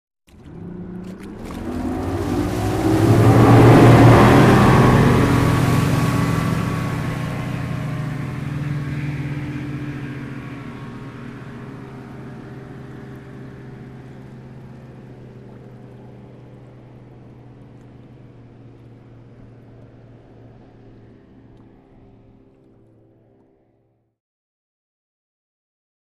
Ranger Boat Start And Fast Away With Shore Wash